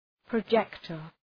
{prə’dʒektər}